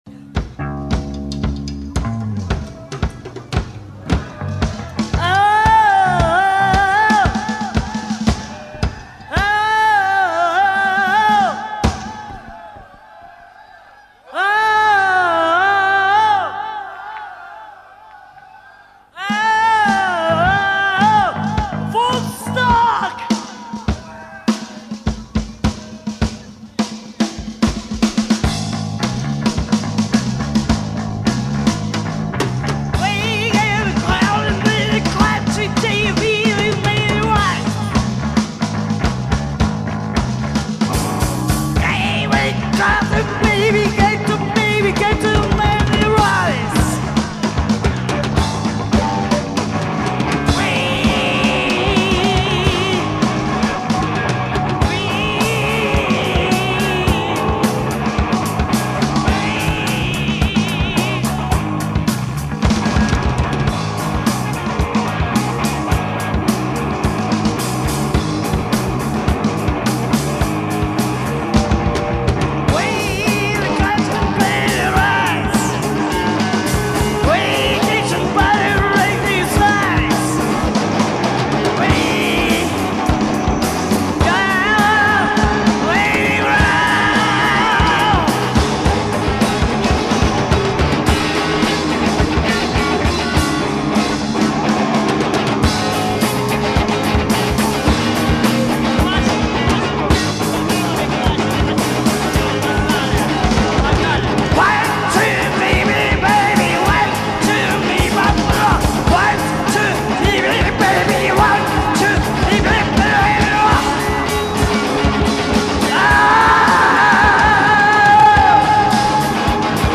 Выступление на фестивале
гитара
барабаны
бас